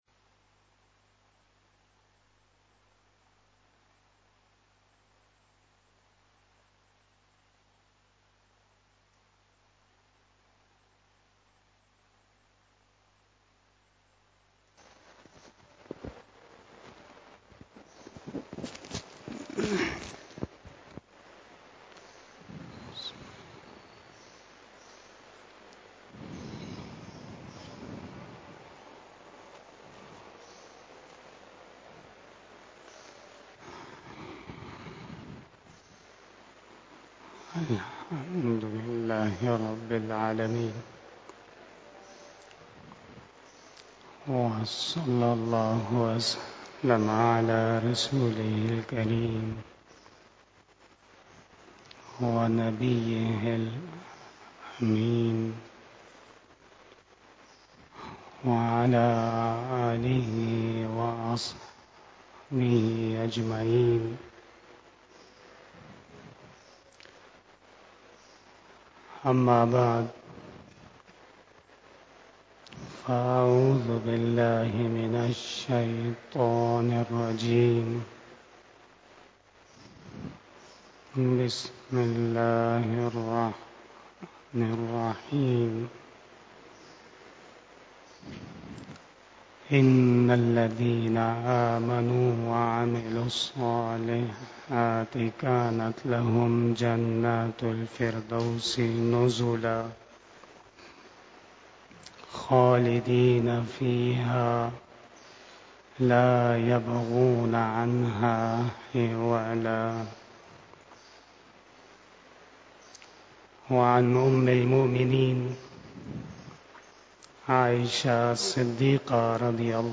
17 KHITAB E JUMMAH 28 April 2023 (07 Shawwal 1444HJ)
بیان جمعۃ المبارک